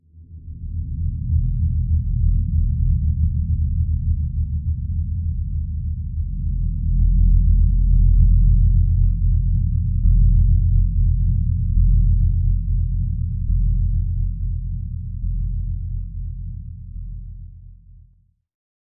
cinematic deep bass rumble
ambience atmosphere bass cinematic dark electronic music noise sound effect free sound royalty free Gaming